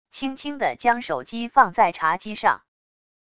轻轻地将手机放在茶几上wav音频